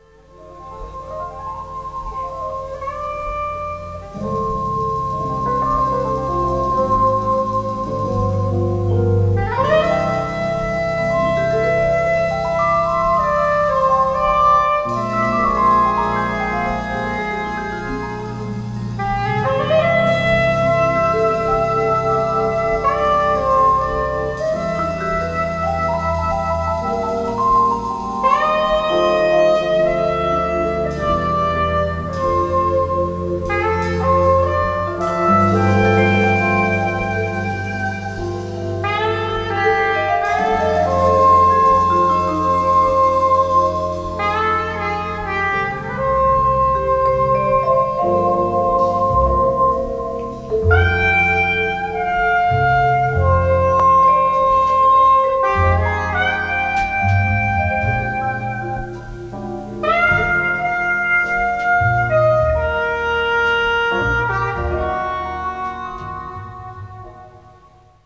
Incidental Music